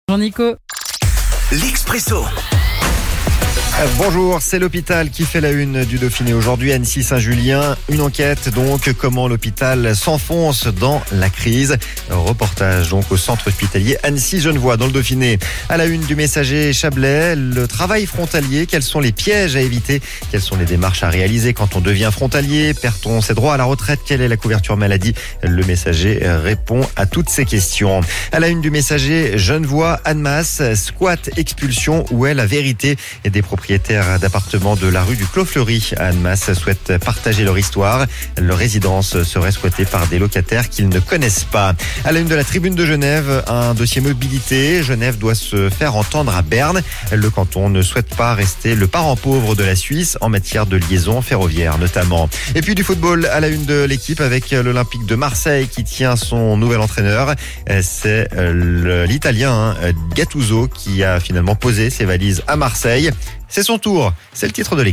La revue de presse